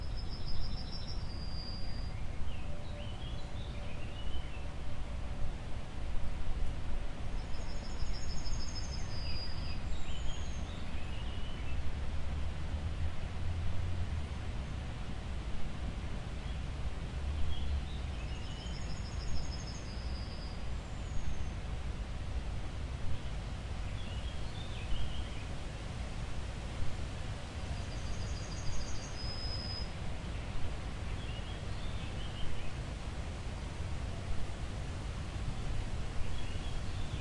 枪声 " 枪声和更多
描述：几个录制的枪声，以及一些背景噪音。
用TSM PR1便携式数字录音机录制，并配有外部立体声麦克风。
标签： 背景 当然 枪声 噪音 培训
声道立体声